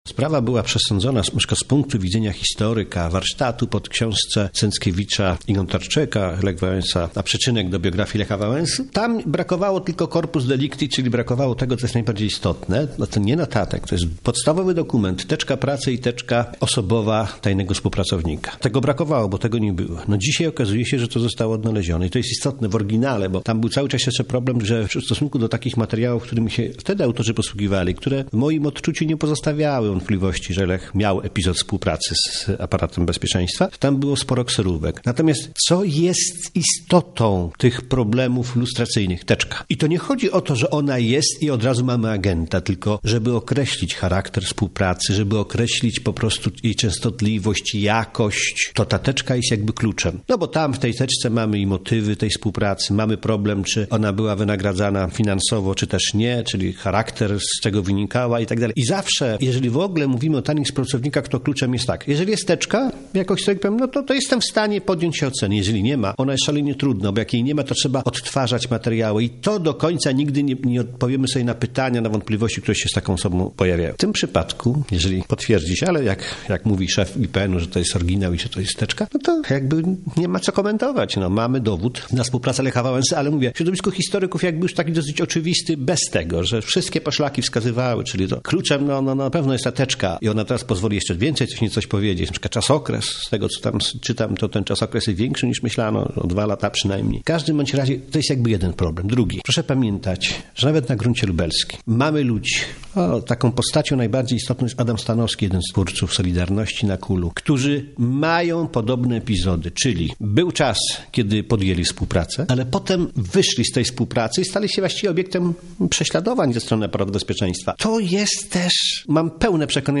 O dokumentach i o ich znaczeniu rozmawiała